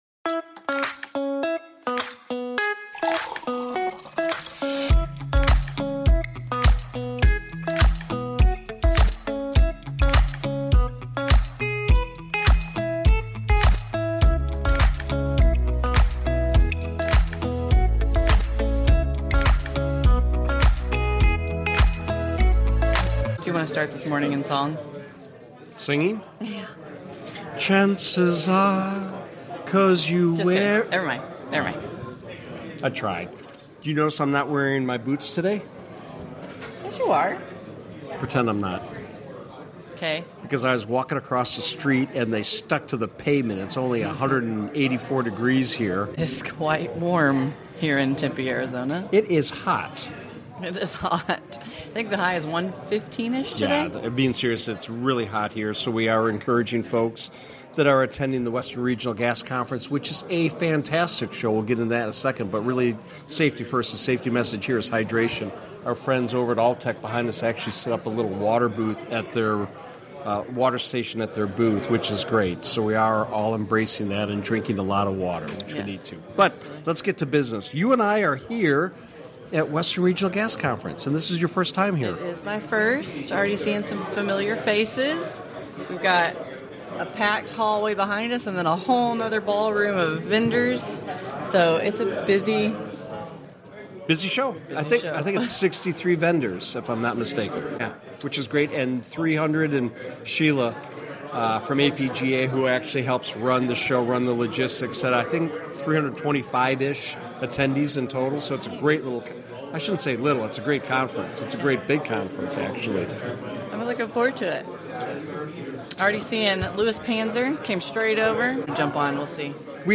Live @ Western Regional Gas Conference